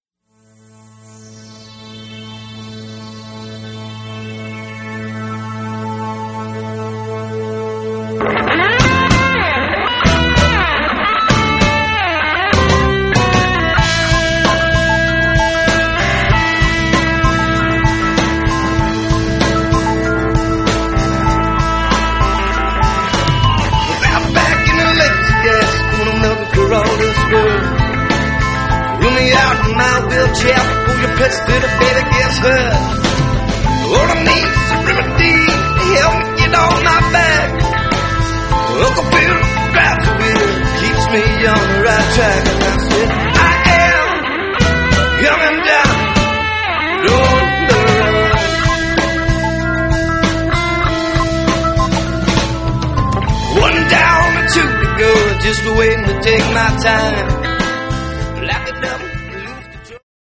World boogie is coming!
Blues
Funk
Rock